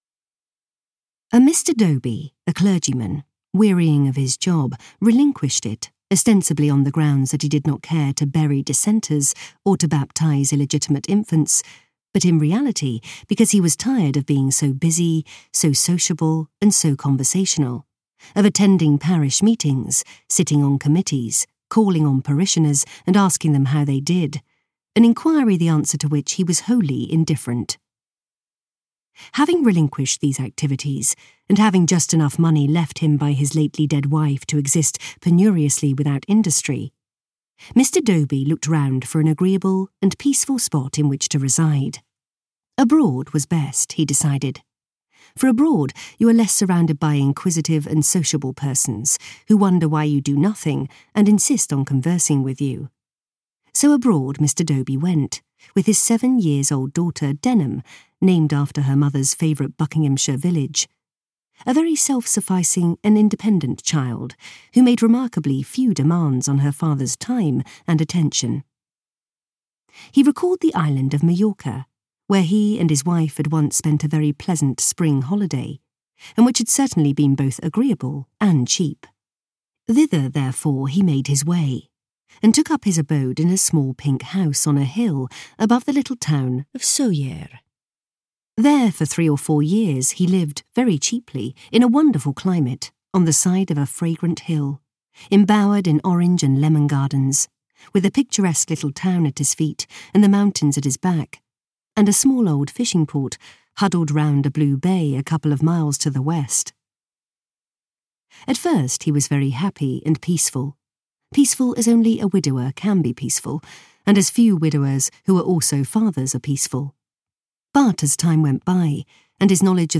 Crewe Train audiokniha
Ukázka z knihy
crewe-train-audiokniha